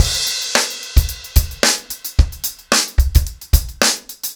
TupidCow-110BPM.35.wav